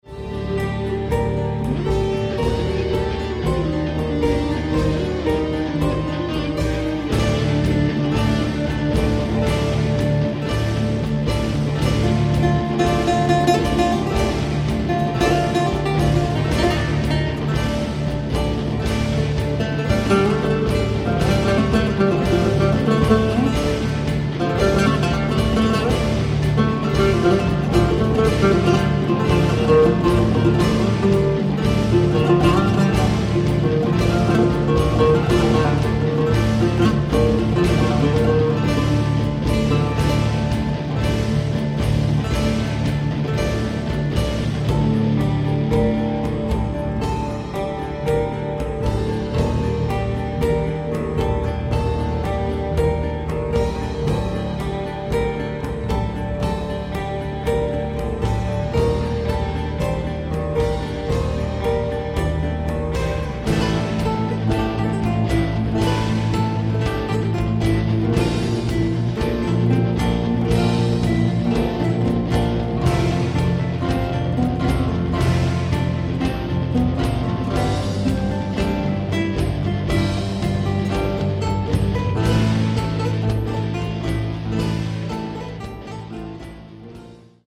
Contemporary , Lute
Oud , Progressive Metal